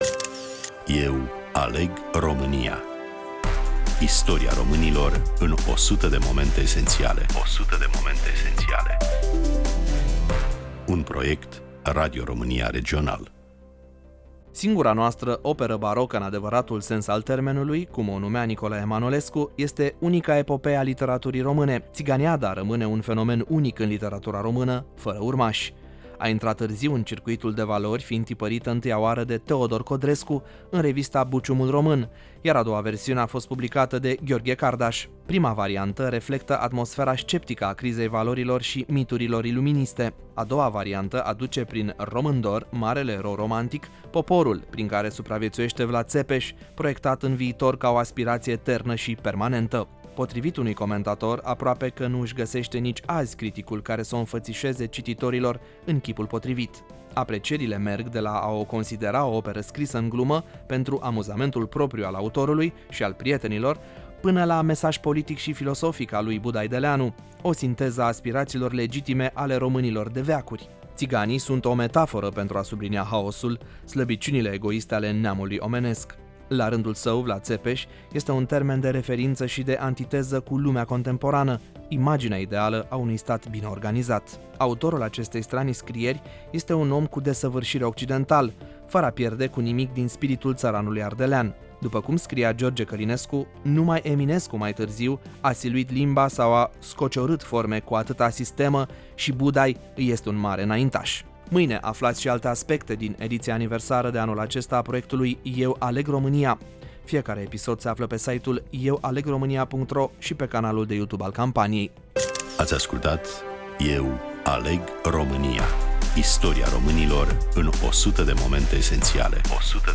Prezentare, voice over